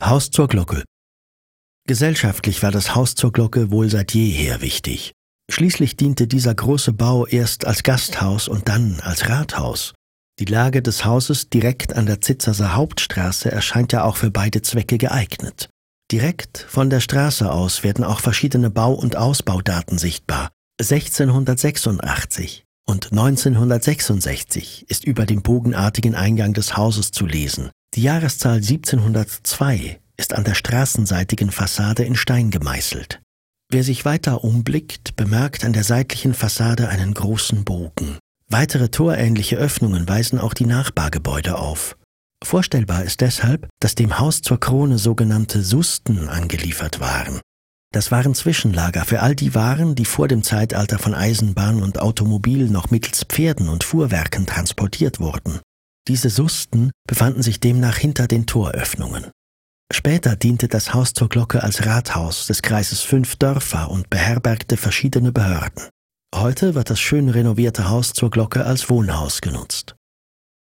More information about the building: PDF Audio guide (MP3)